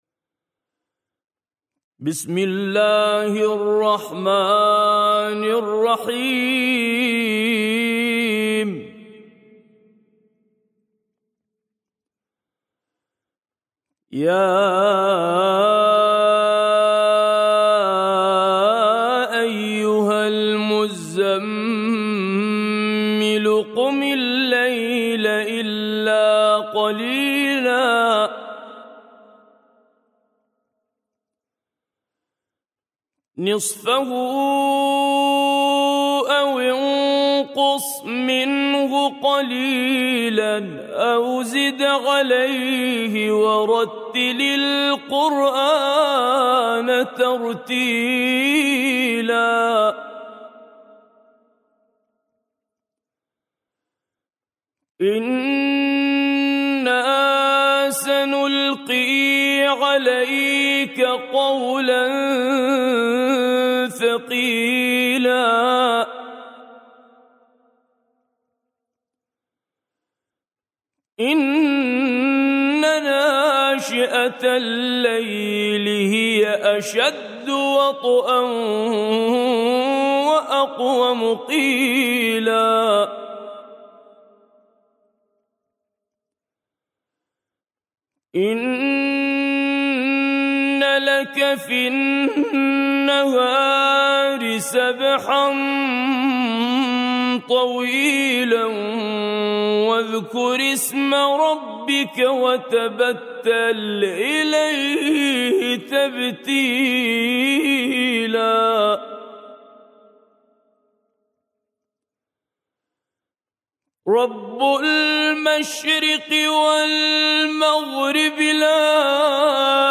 سورة المزمل - الطور العراقي - لحفظ الملف في مجلد خاص اضغط بالزر الأيمن هنا ثم اختر (حفظ الهدف باسم - Save Target As) واختر المكان المناسب